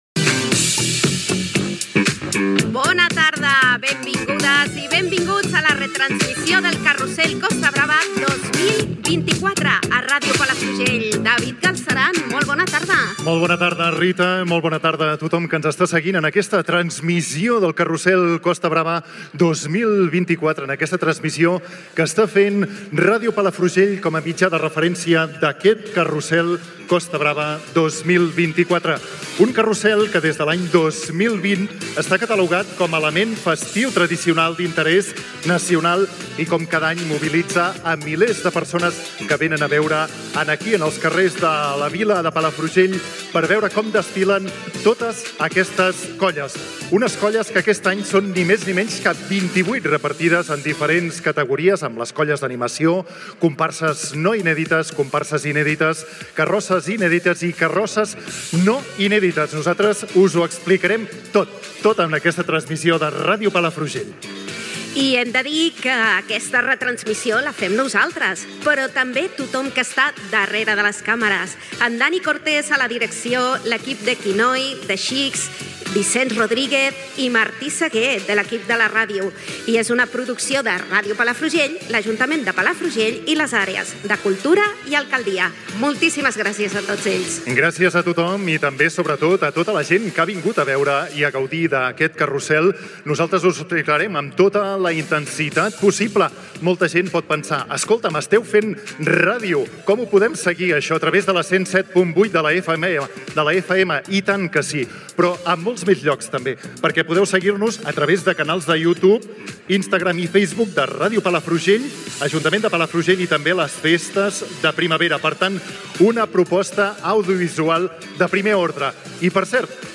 Transmissió del 62è Carroussel Costa Brava de les Festes de Primavera 2024. Presentació, equip, llocs on es pot seguir la transmissió, itinerari, connexió amb l'inici del carroussel, dades de la desfilada
Entreteniment